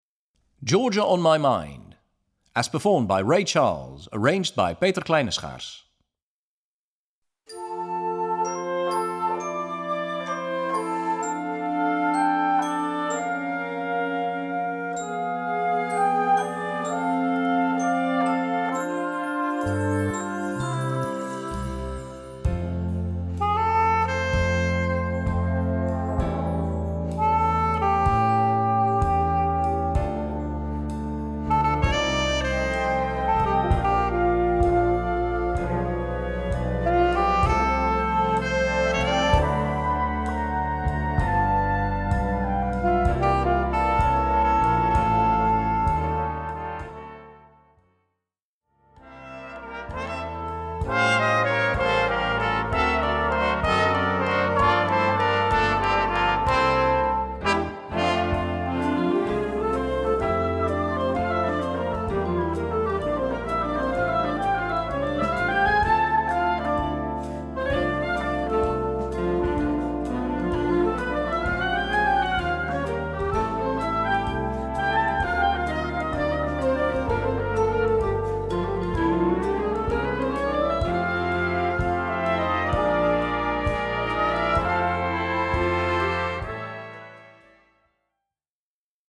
Gattung: Moderner Einzeltitel
Besetzung: Blasorchester
Arrangement für Blasorchester